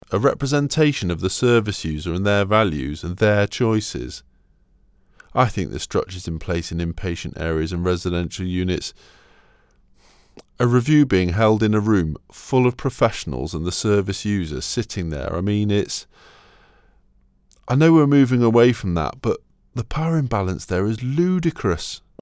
section5-voice-ot.mp3